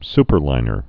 (spər-līnər)